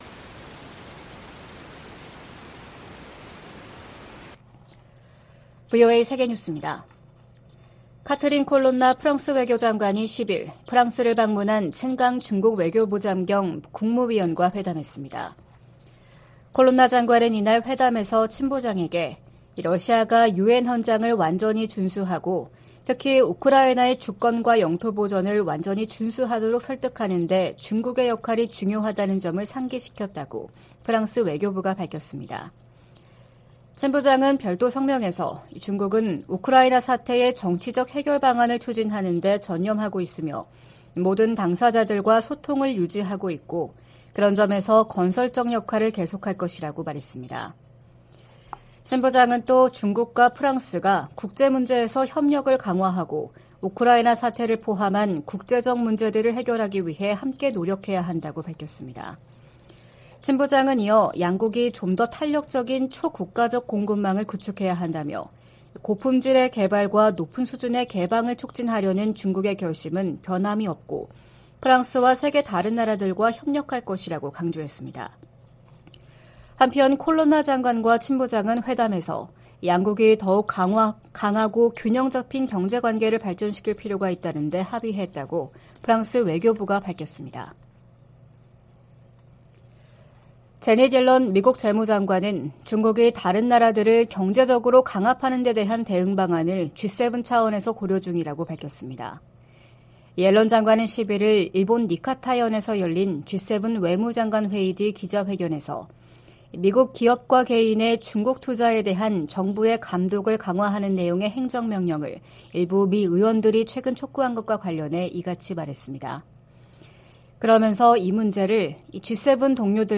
VOA 한국어 '출발 뉴스 쇼', 2023년 5월 12일 방송입니다. 북한이 사이버 활동으로 미사일 자금 절반을 충당하고 있다고 백악관 고위 관리가 말했습니다. 미한 동맹이 안보 위주에서 국제 도전 과제에 함께 대응하는 관계로 발전했다고 미 국무부가 평가했습니다. 미 국방부가 미한일 3국의 북한 미사일 정보 실시간 공유를 위해 두 나라와 협력하고 있다고 확인했습니다.